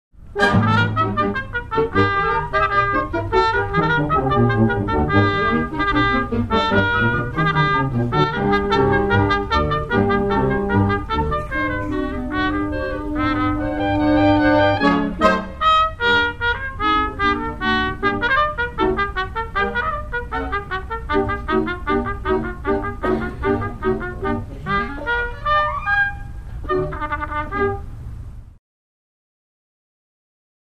Solo Trumpet with Concert Band